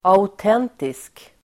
Ladda ner uttalet
Uttal: [a_ot'en:tisk]
autentisk.mp3